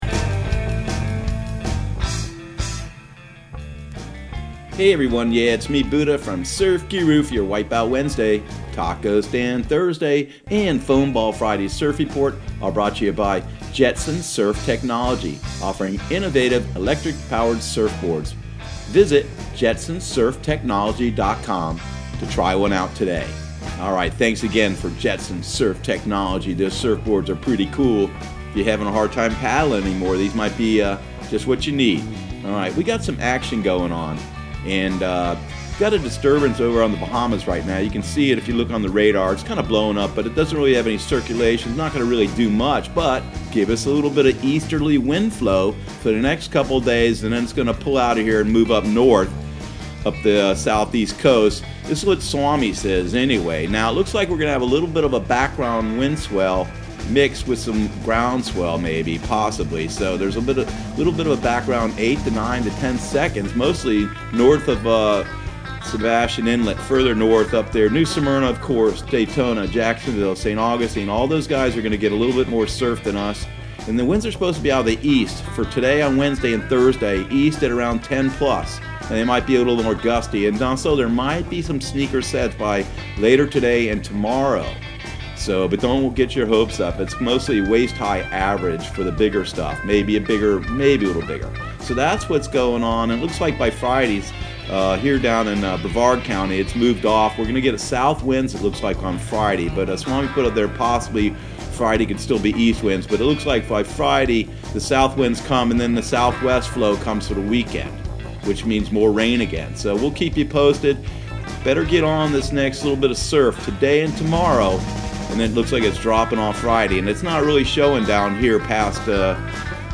Surf Guru Surf Report and Forecast 08/21/2019 Audio surf report and surf forecast on August 21 for Central Florida and the Southeast.